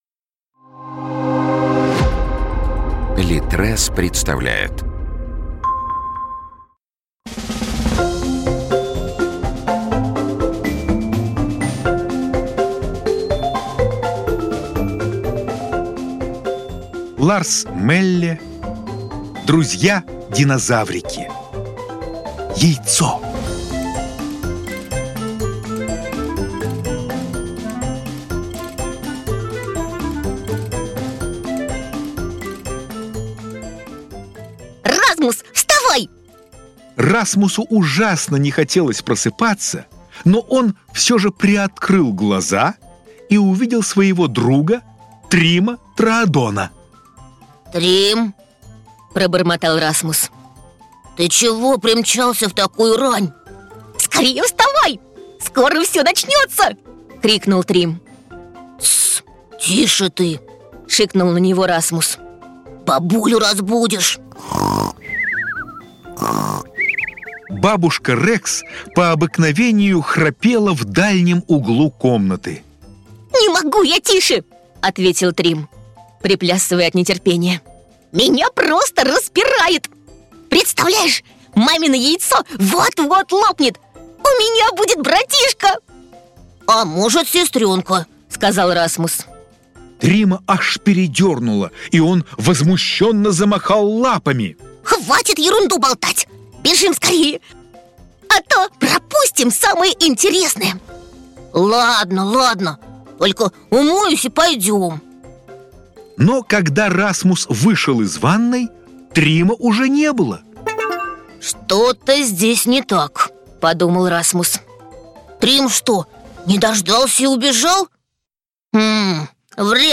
Аудиокнига Друзья-динозаврики. Яйцо | Библиотека аудиокниг